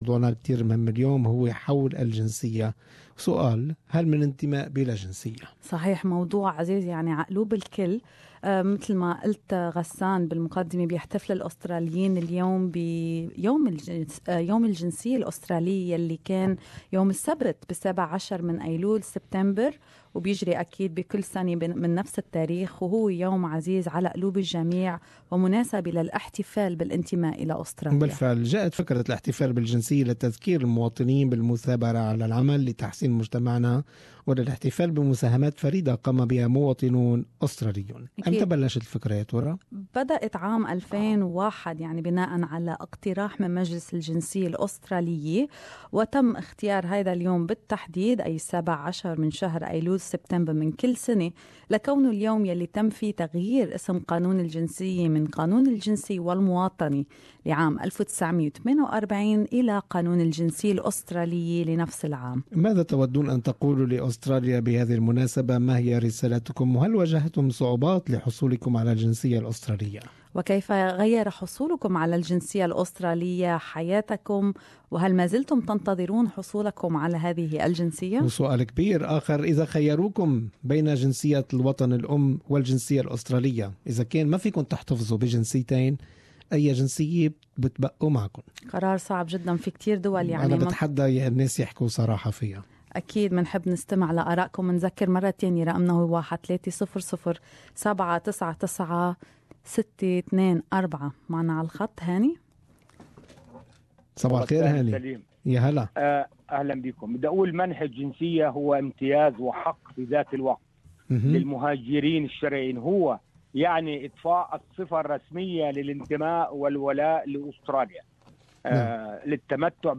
Can you belong to a country without holding its citizenship? the 17th of September is Australian citizenship day, what is your message to the countru in this special day? More in this Talk back